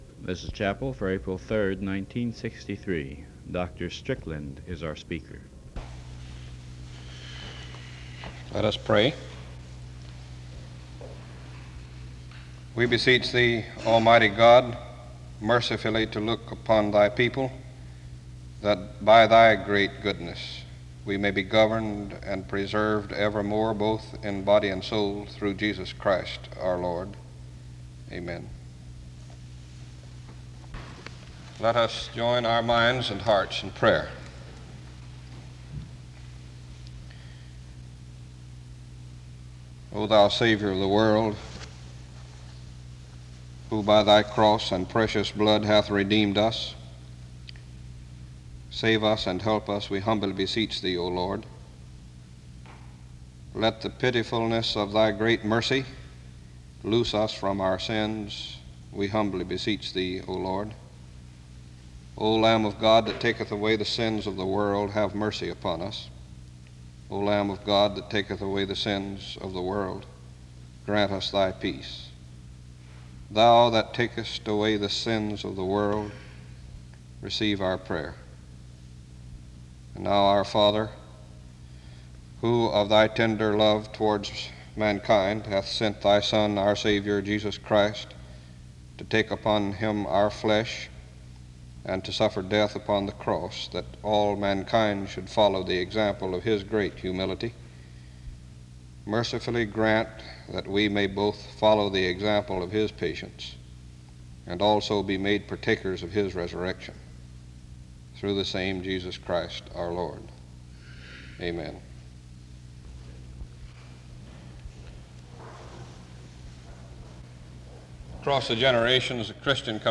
There is a closing song that plays from 17:42-22:10.
SEBTS Chapel and Special Event Recordings SEBTS Chapel and Special Event Recordings